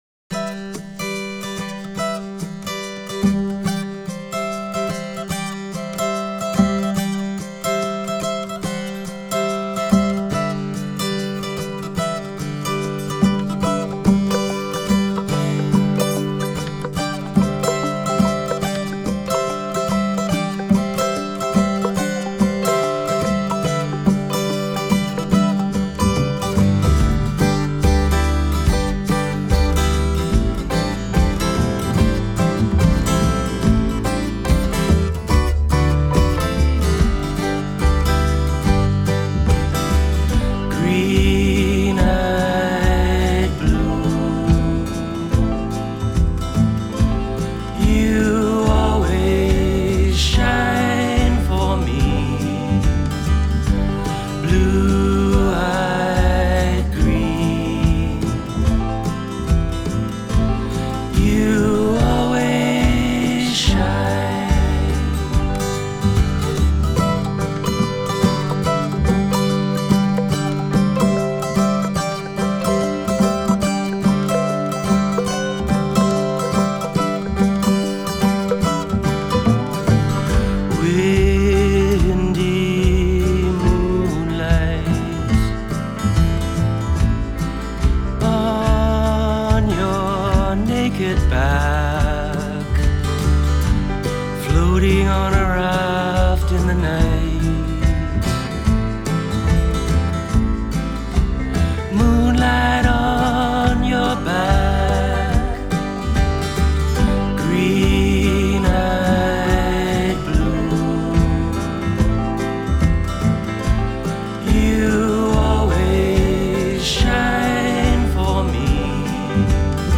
vocals/guitars/piano/percussion
vocals/harmonica
bass